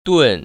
[dùn] 뚠